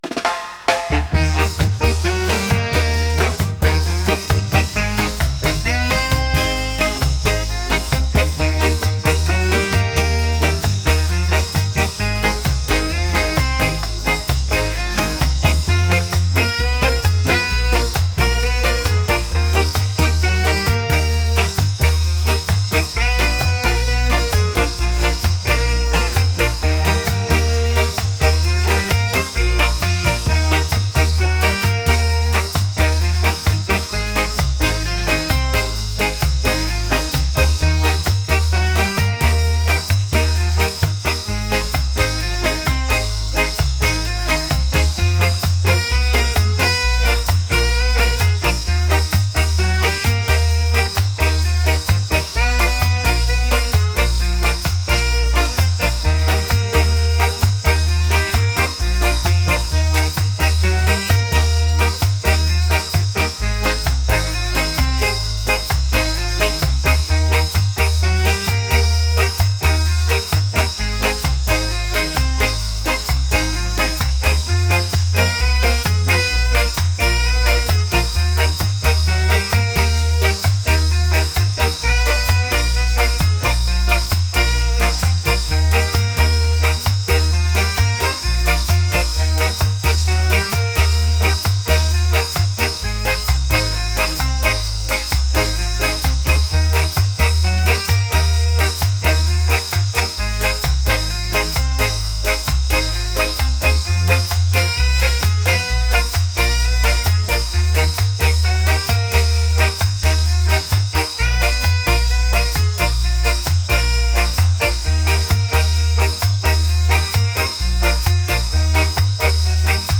reggae | groovy